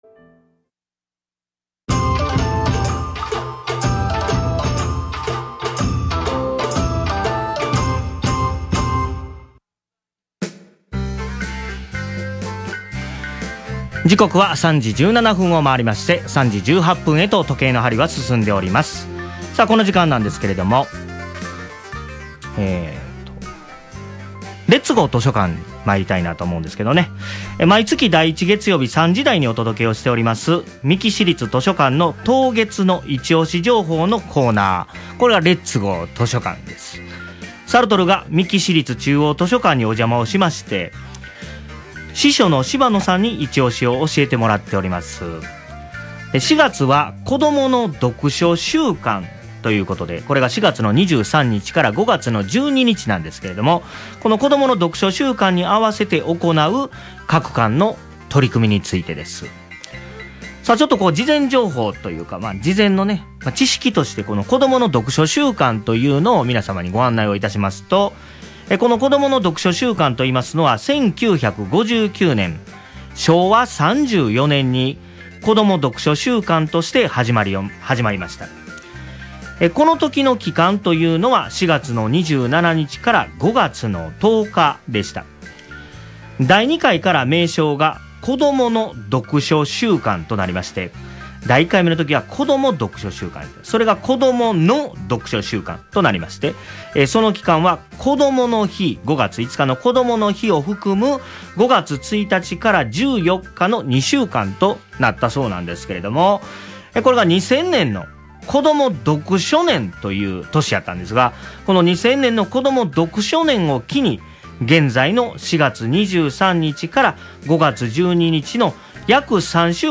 こいのぼりのぬりえ の配布 4月23日（土）～5月5日（木） 三木市立青山図書館 ☆ ところで、今回、防音効果の高いお部屋で収録をさせていただきました。